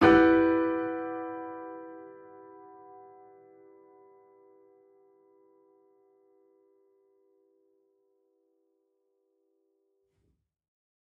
Index of /musicradar/gangster-sting-samples/Chord Hits/Piano
GS_PiChrd-Emin6+9.wav